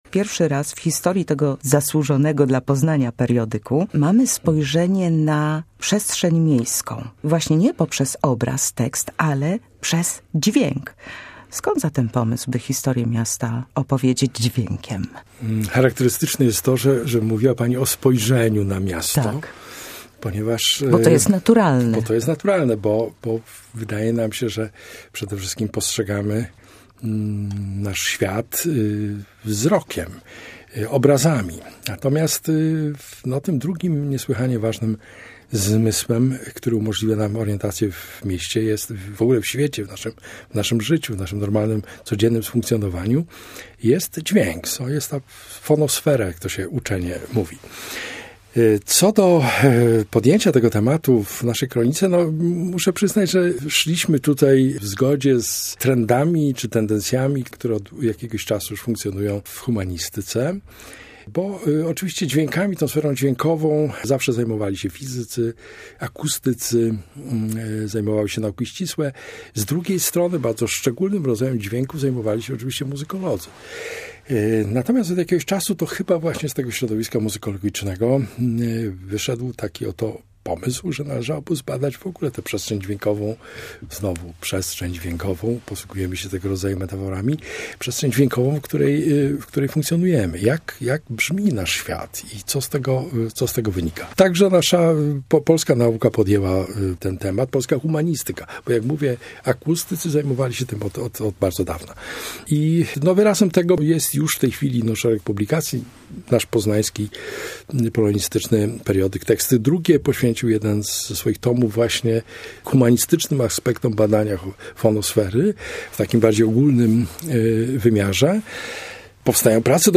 Spis treści - 22.02.2026 - Radio Poznań